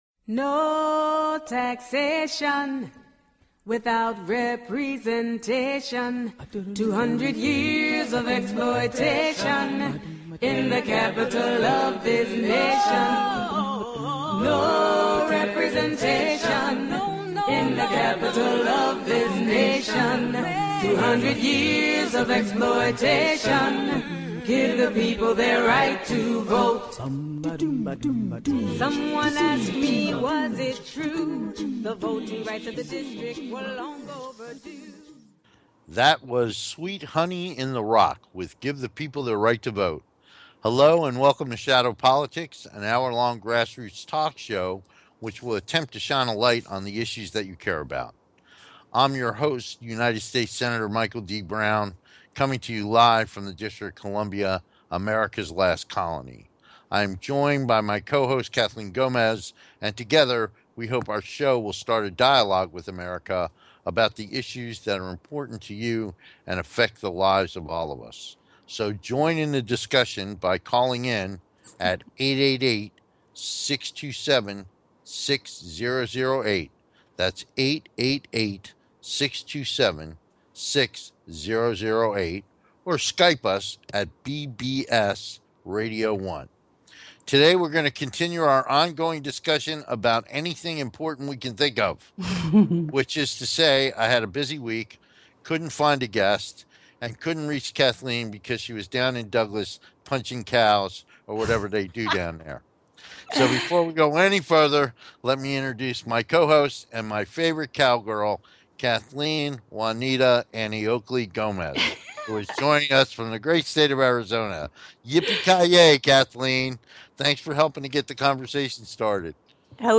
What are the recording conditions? We look forward to having you be part of the discussion so call in and join the conversation.